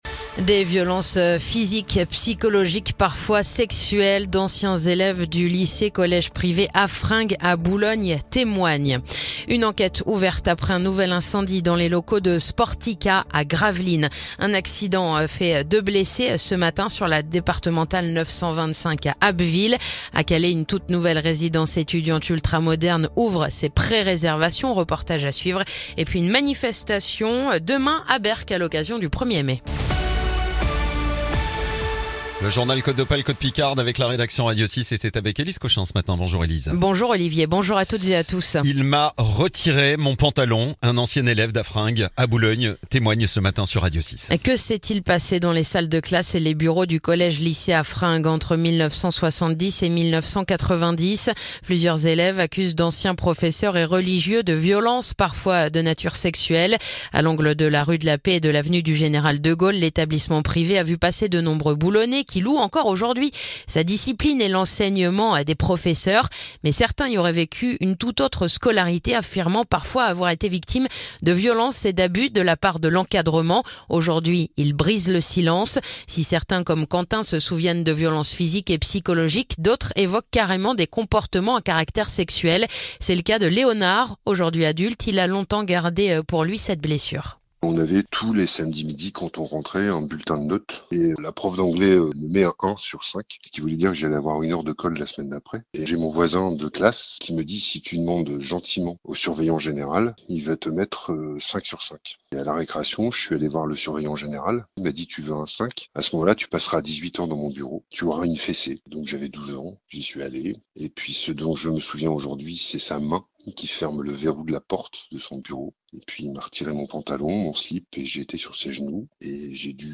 Le journal du mercredi 30 avril